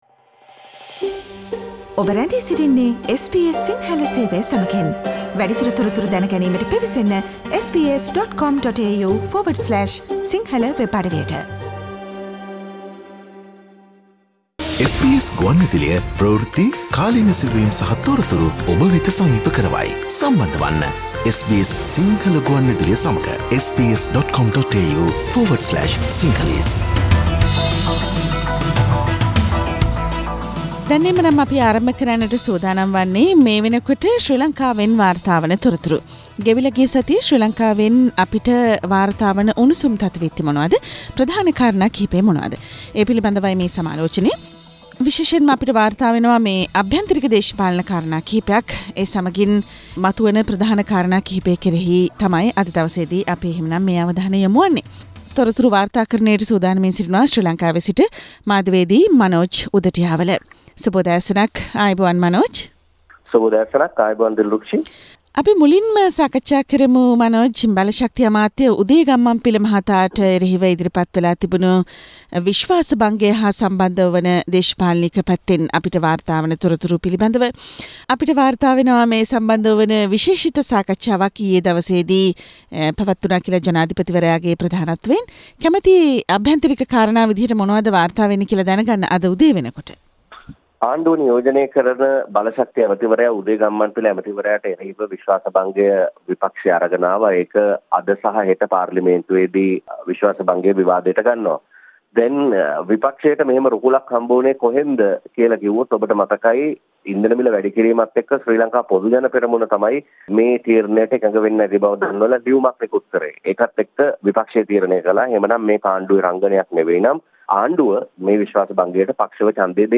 සතියේ ශ්‍රී ලාංකීය පුවත් සමාලෝචනය